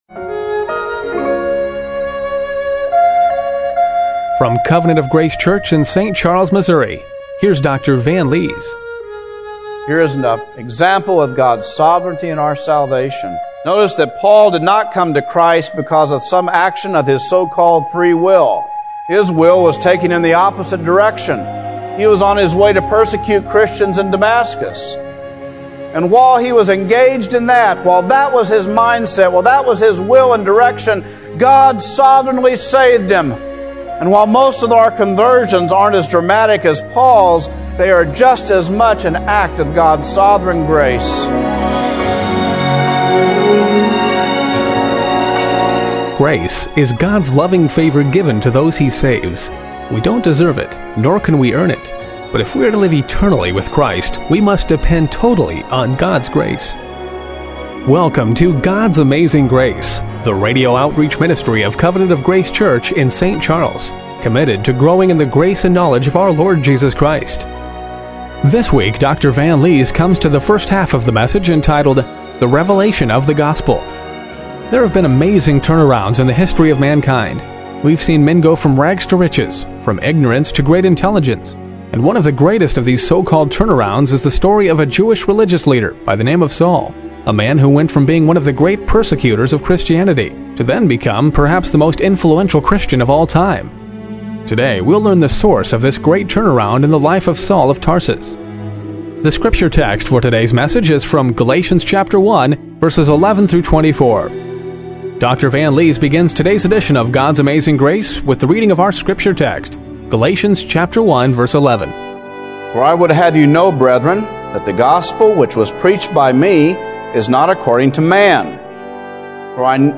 Galatians 1:11-24 Service Type: Radio Broadcast What was the source of the great turnaround in the life of Saul of Tarsus?